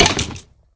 minecraft / sounds / mob / skeleton / hurt1.ogg
hurt1.ogg